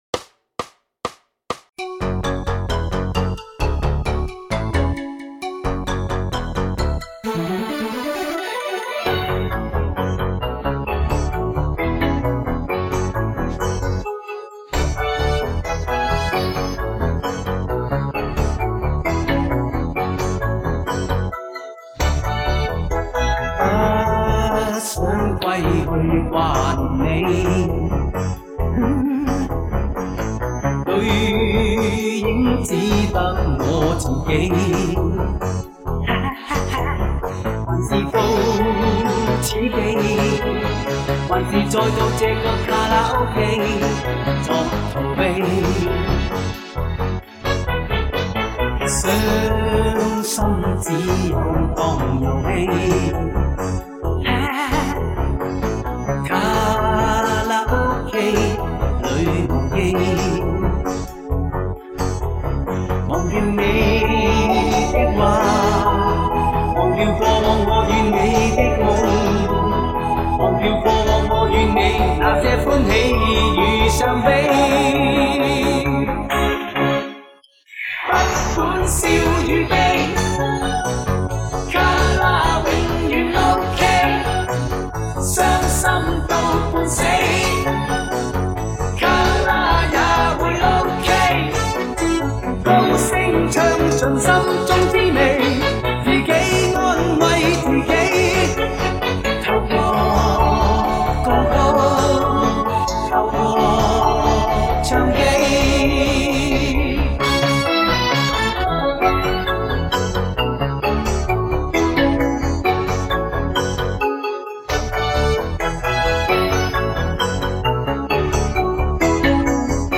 经典歌曲